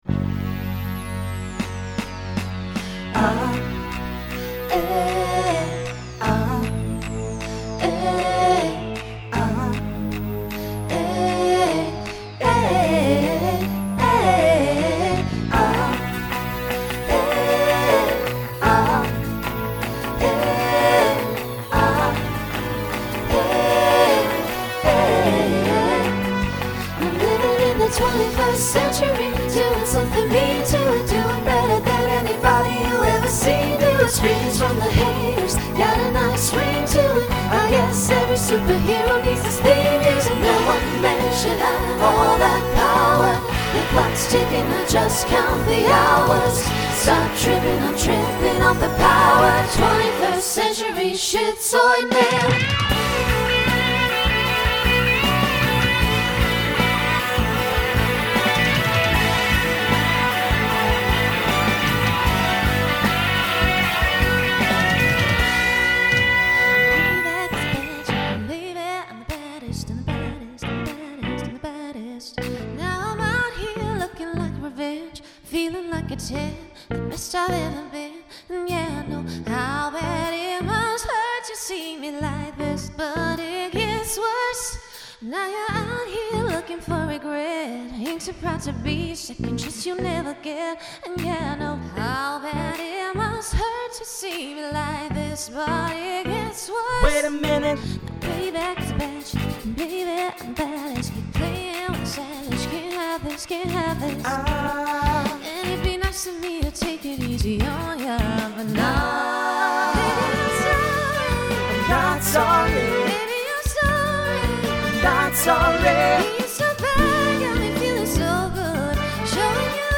SATB/TTB
Voicing Mixed
Genre Pop/Dance , Rock